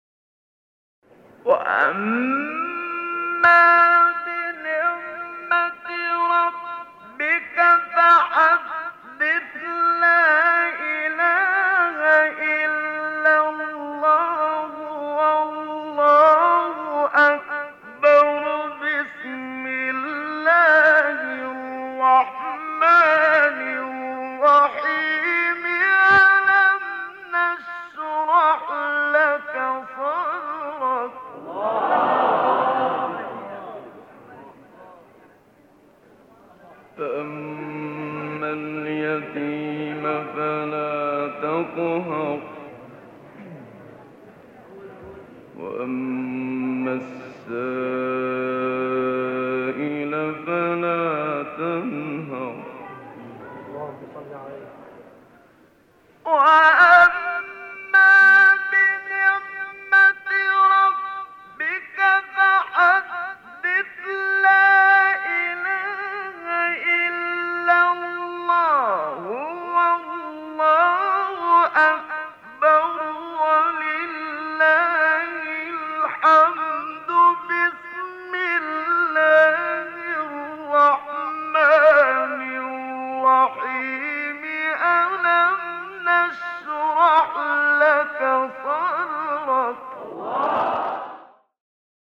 قطعه تلاوت منشاوی